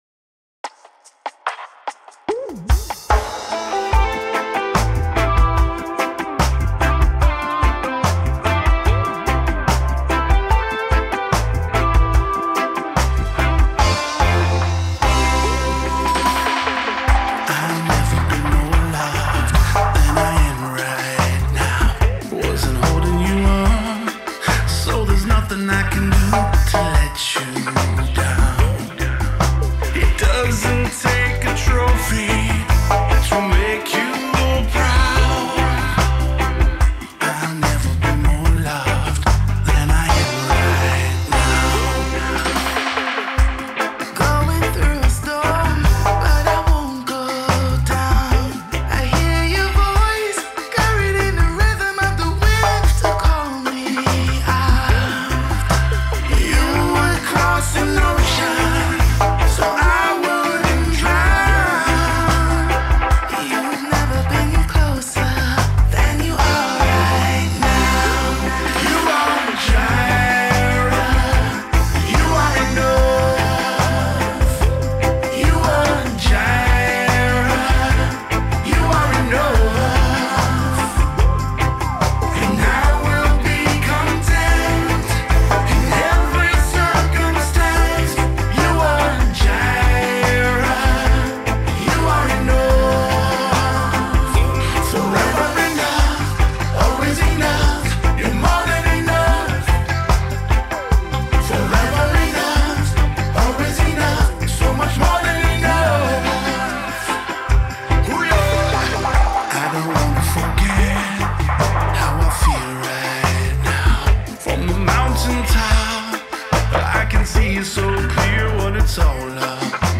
Gospel reggae cover